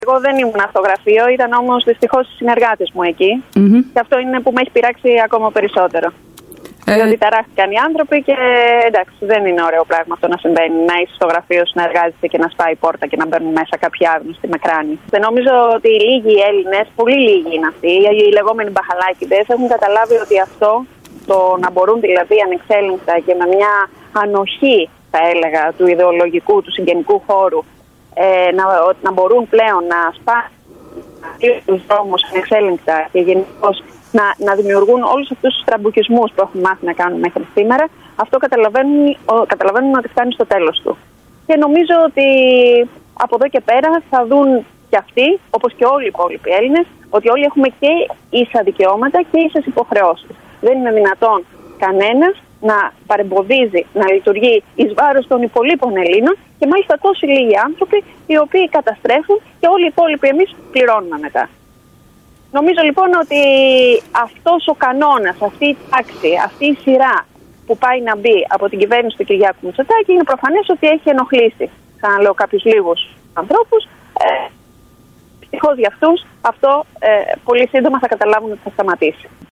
Για την επίθεση στο πολιτικό της γραφείο μίλησε στην πρωινή ενημερωτική  εκπομπή της ΕΡΤ Πάτρας η Βουλευτής της ΝΔ στην Αχαΐα Χριστίνα Αλεξοπούλου.